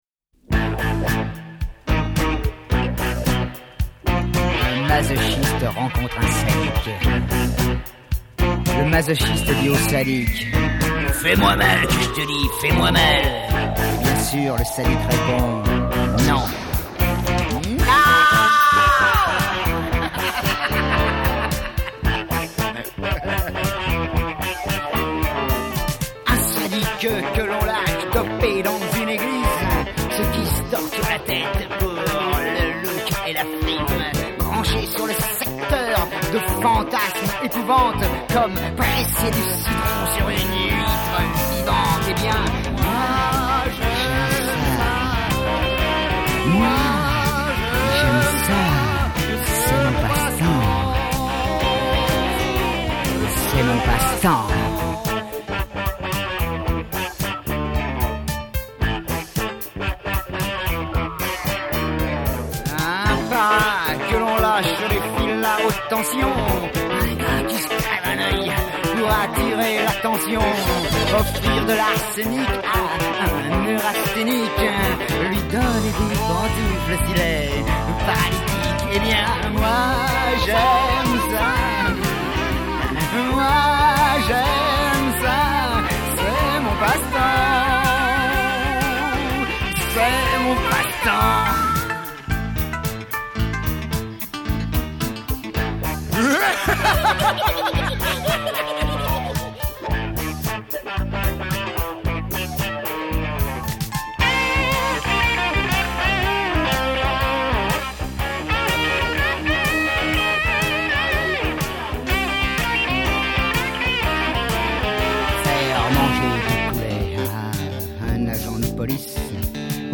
Chant& Choeurs
Clavier
Guitares
Percussion